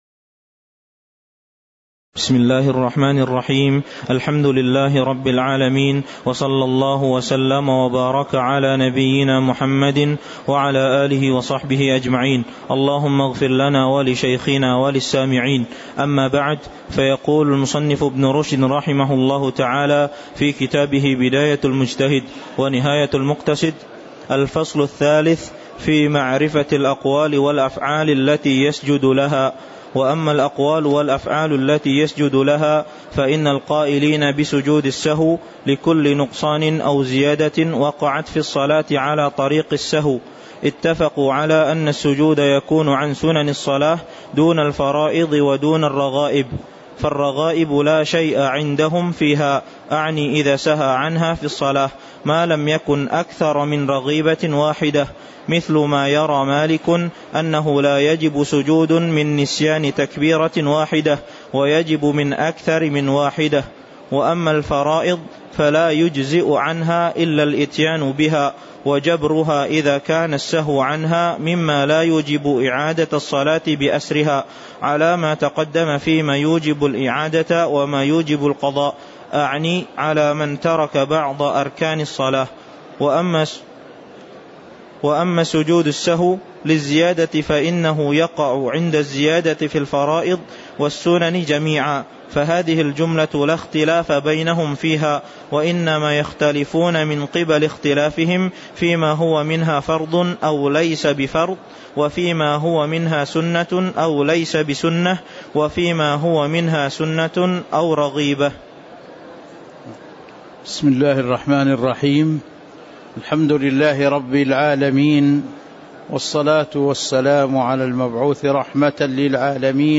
تاريخ النشر ١٩ ربيع الثاني ١٤٤٤ هـ المكان: المسجد النبوي الشيخ